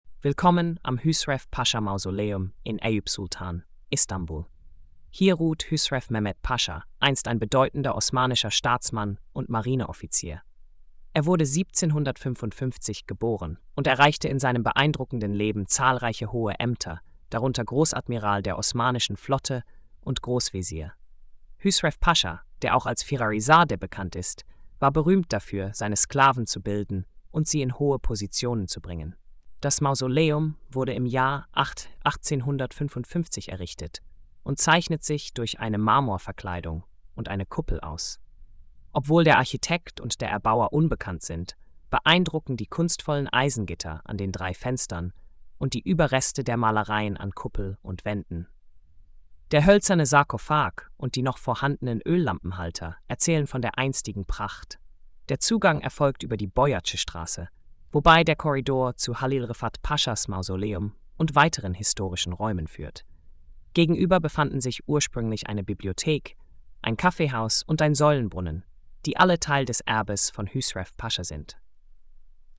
Audıo Erzählung: